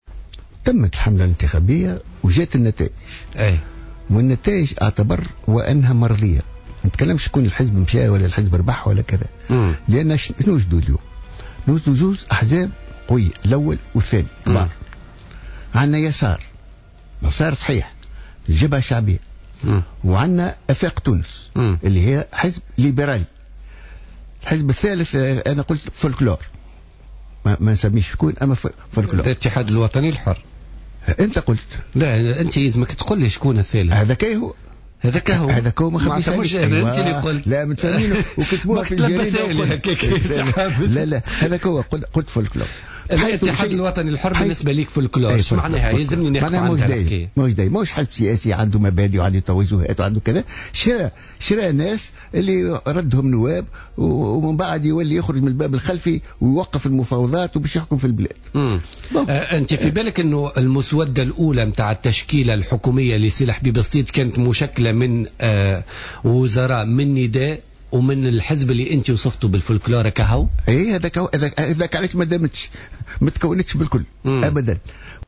وصف رئيس الحركة الدستورية حامد القروي ضيف بوليتيكا اليوم الاربعاء، نتائج الانتخابات التشريعية بالـ"مرضية" بعد ما أسفرت عن تنوع للمشهد السياسي وحضور لكافة الاطياف من يسار ويمين وليبراليين ودساترة بالإضافة لما أسماه بالـ"الفلكلور" في إشارة للاتحاد الوطني الحر والذي لا يمكن اعتباره حزبا سياسيا على حد تعبيره.